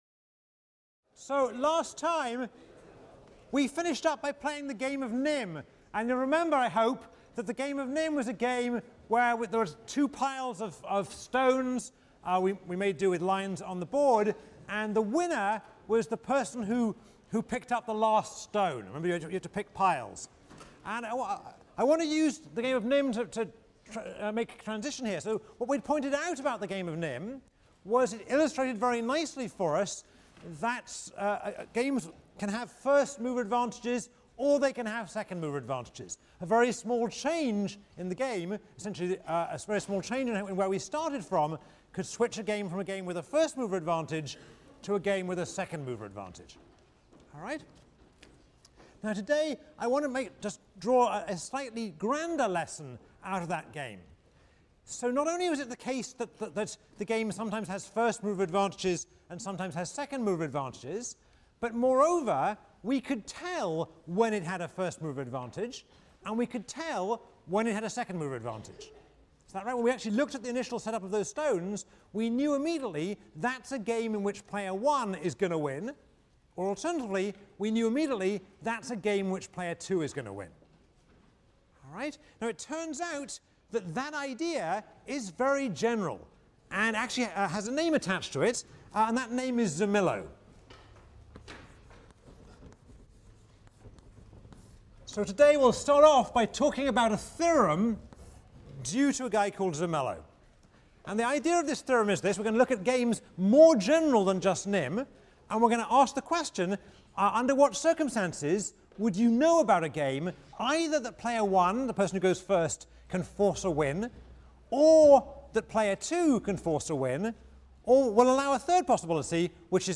ECON 159 - Lecture 15 - Backward Induction: Chess, Strategies, and Credible Threats | Open Yale Courses